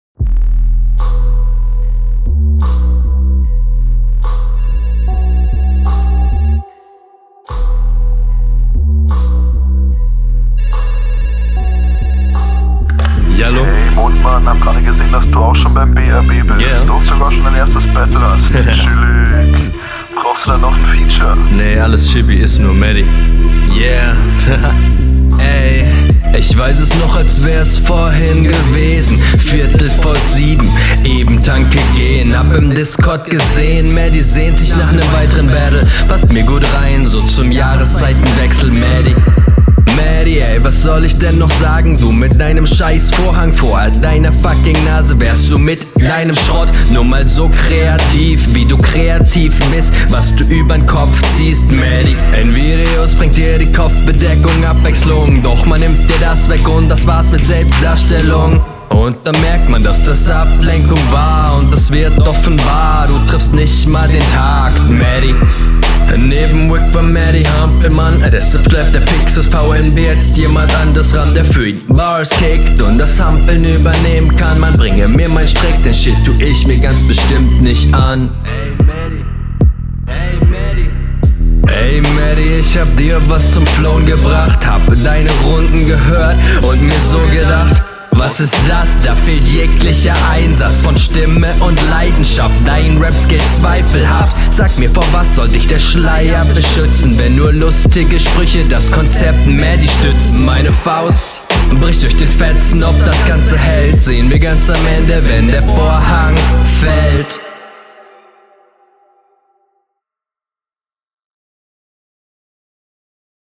Schöne Mische und angenehmer Stimmeinsatz, der aber leider teilweise bissl unverständlich ist.
Intro klang akustisch nice, fand ich aber eher unnötig. Geiler Stimmeinsatz vorab, aber schonmal!